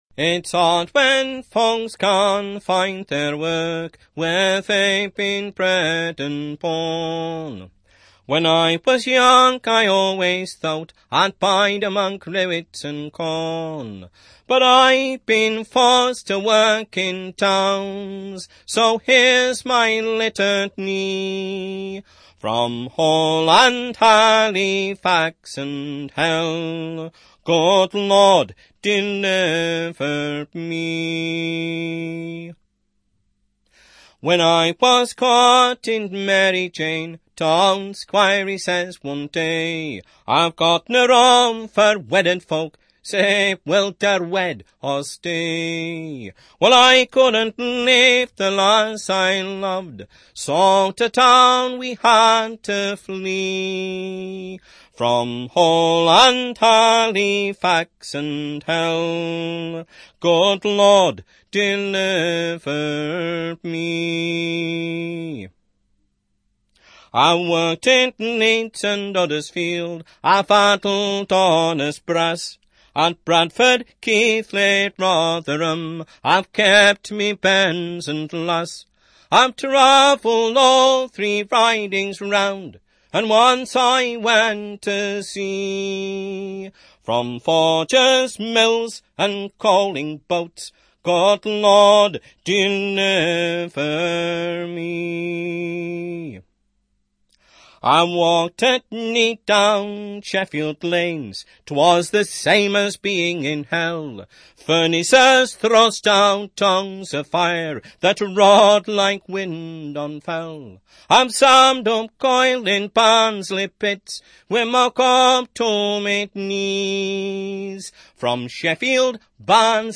Barnsley
E Aeolian